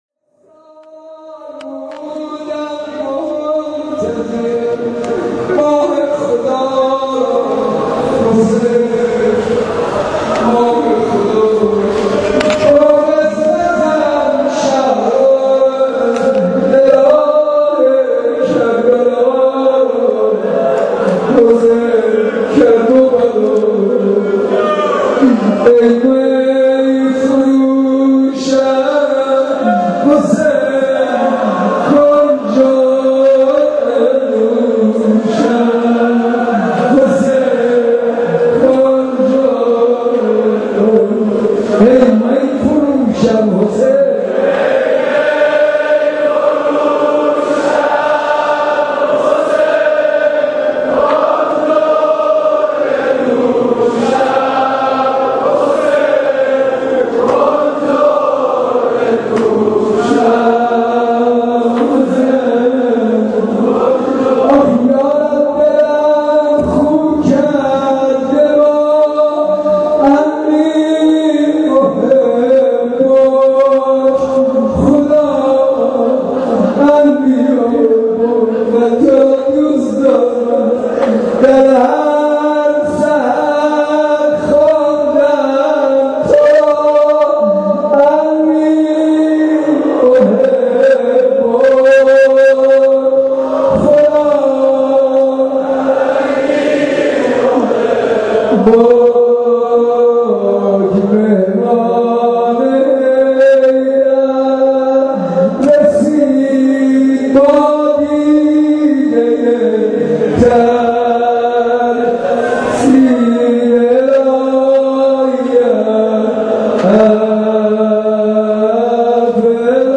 • حاج منصور ارضی, شب اول رمضان 92, شب اول رمضان 92 حاج منصور ارضی, رمضان 92 حاج منصور ارضی, رمضان 92 حاج منصور, نوای عرش, صوت شب اول مسجد ارک رمضان 92